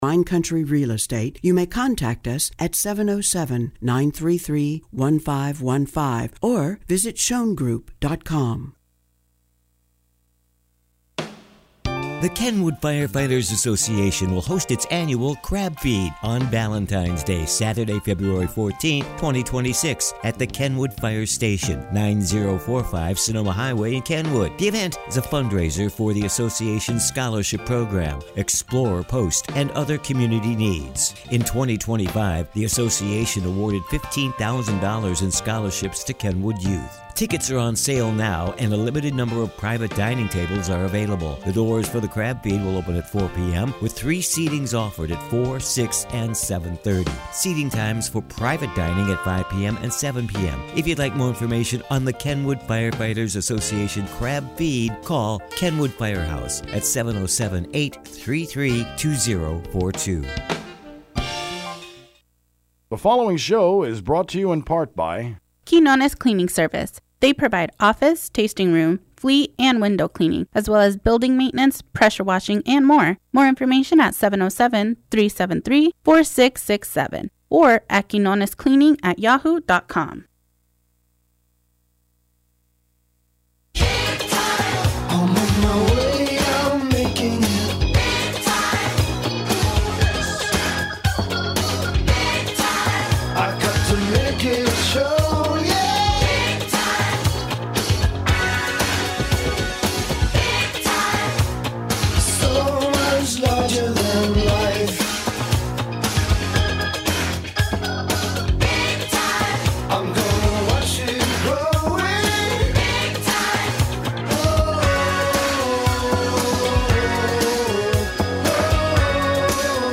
KSVY 91.3 radio